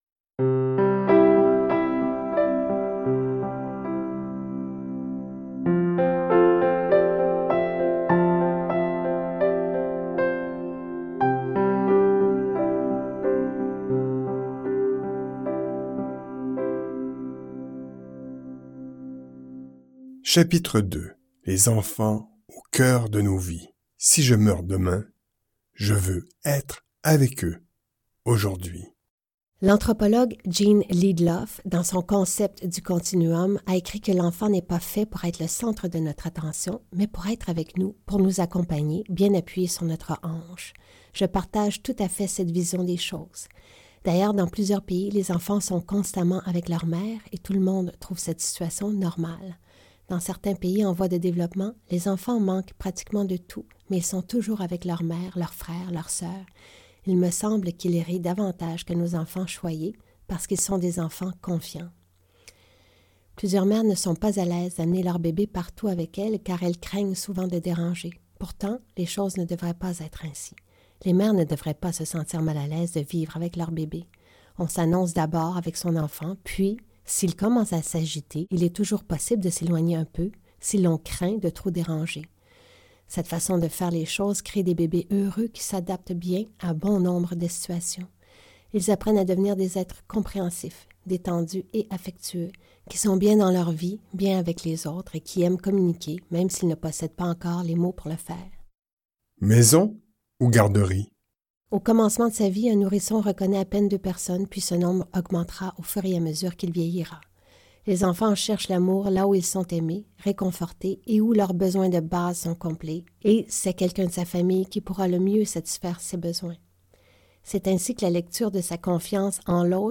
Un livre audio pour se faire du bien au quotidien !